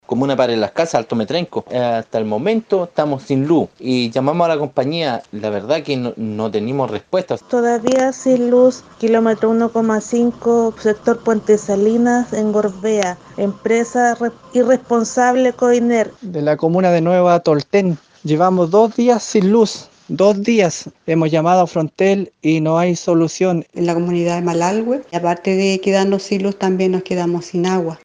Así reaccionaron los auditores de La Radio.